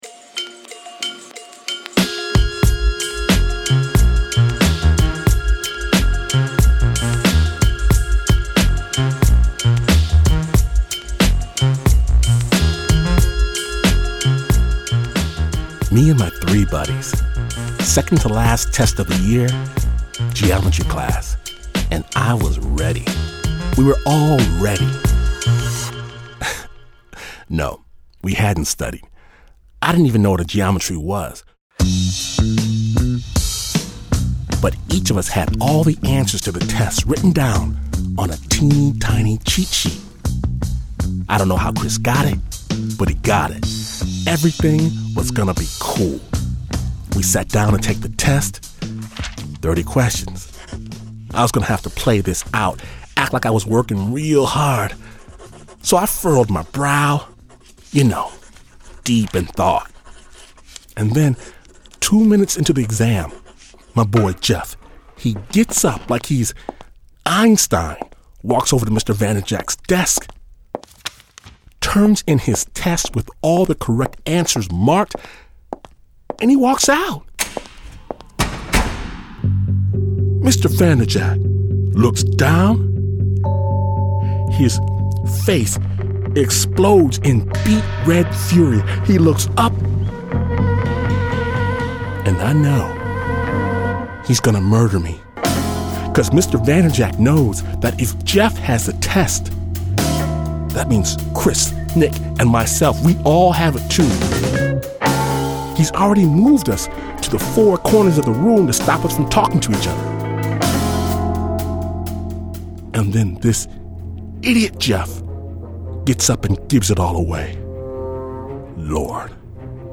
Snap Judgment (Storytelling, with a BEAT) mixes real stories with killer beats to produce cinematic, dramatic, kick-ass radio. Snap’s raw, musical brand of storytelling dares listeners to see the world through the eyes of another.